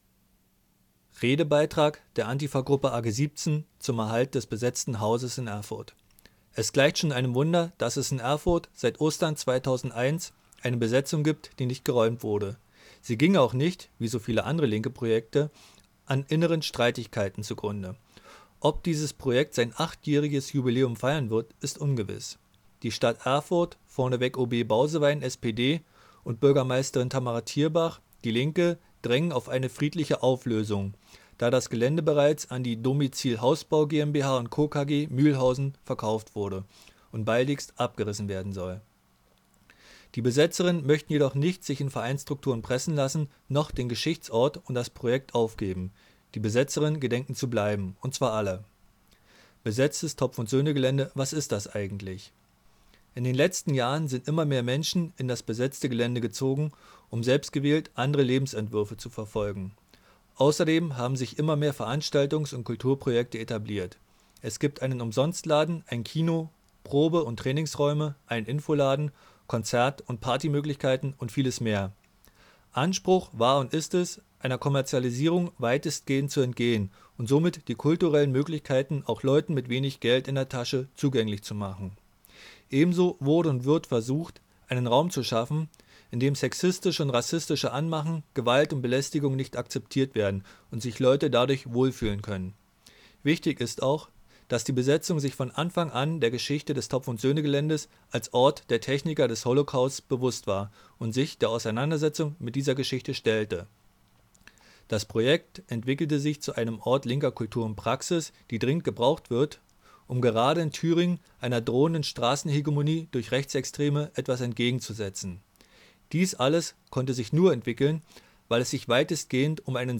Einen Redebeitrag der Antifa AG17 könnt ihr
Redebeitrag-B-Haus.ogg